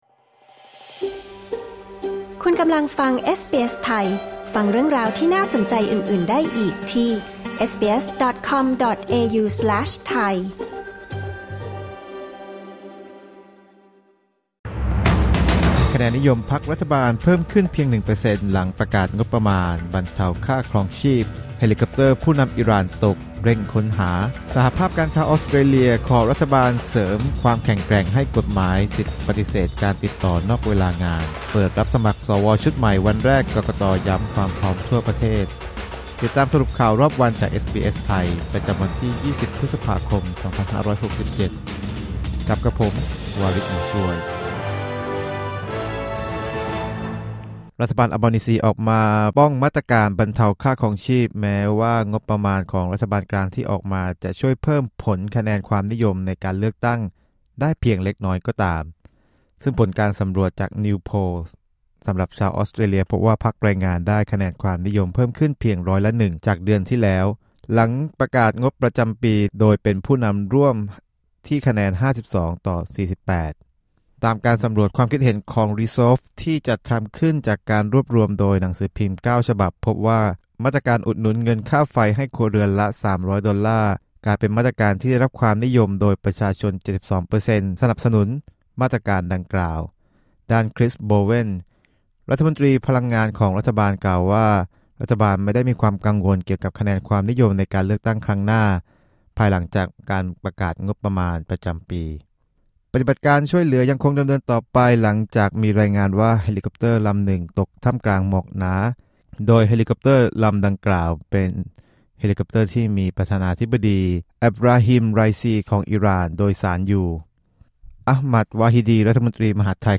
สรุปข่าวรอบวัน 20 พฤษภาคม 2567